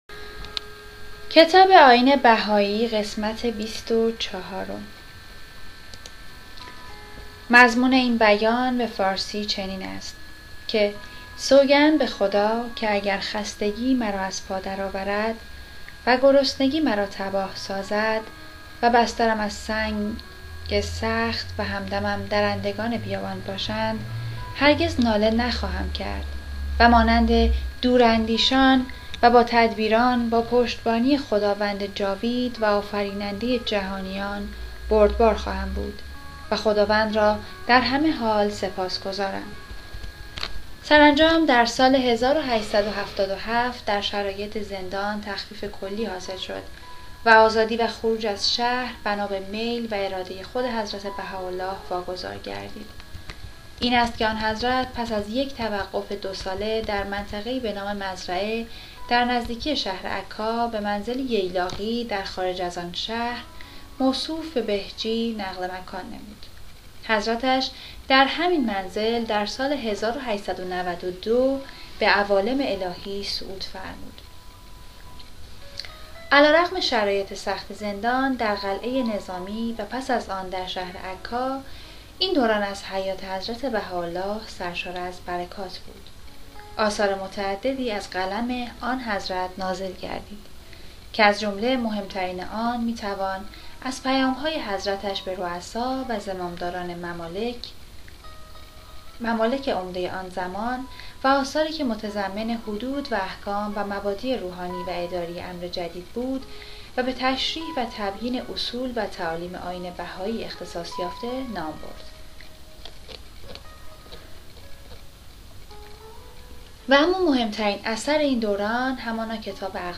کتاب صوتی «نگرشی کوتاه به تاریخ و تعالیم دیانت بهائی» | تعالیم و عقاید آئین بهائی